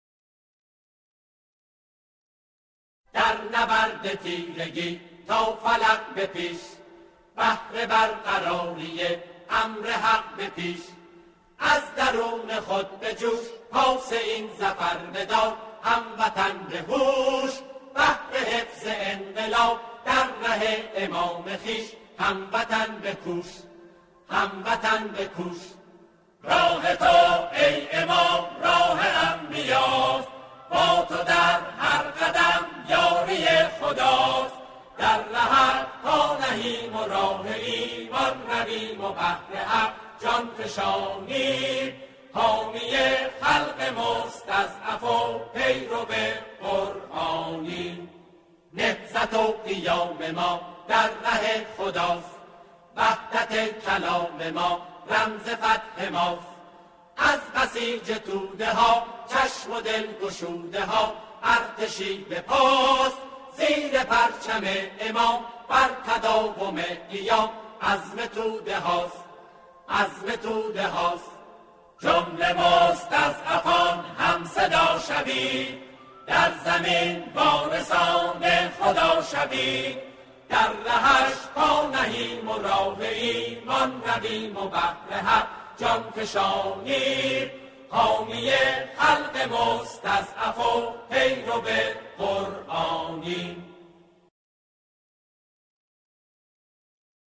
به صورت آکاپلا